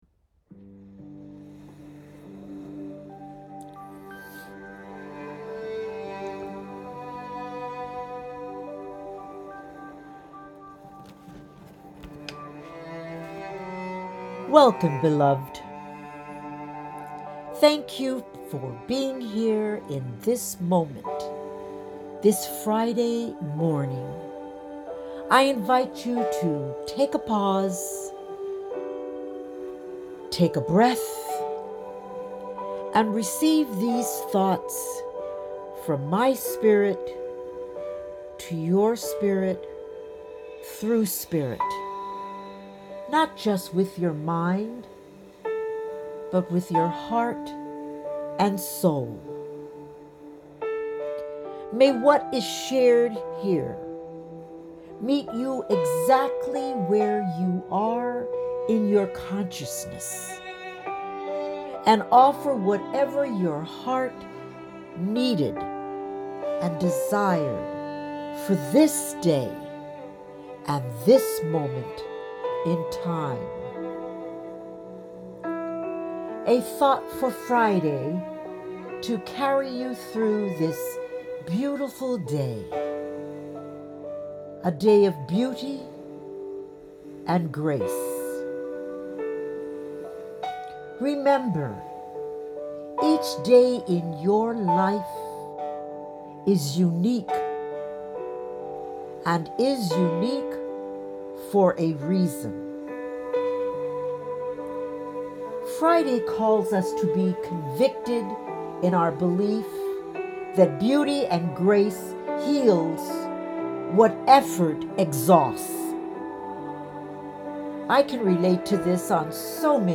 The recordings are a reflection of the writings and are not edited because I do not want to alter what is coming to me through “Great Spirit”.
Thank You Jim Brickman for your beautiful music that vibrates through this recording.